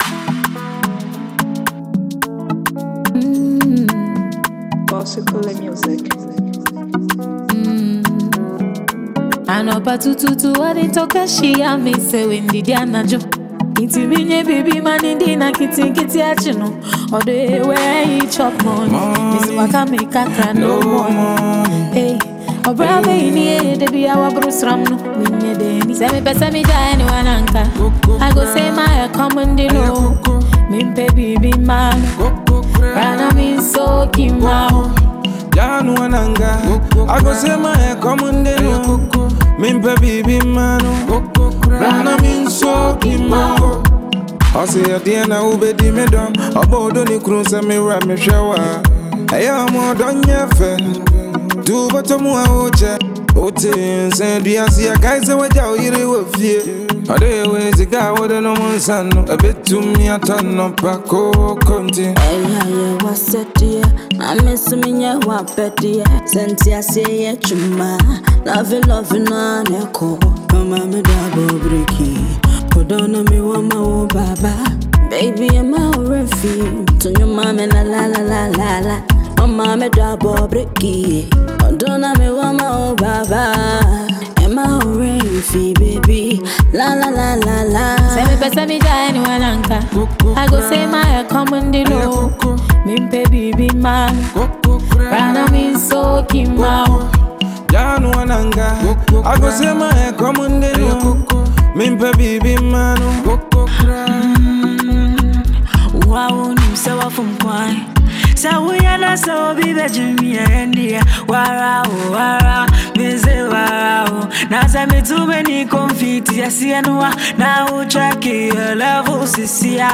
• Genre: Highlife / Afrobeat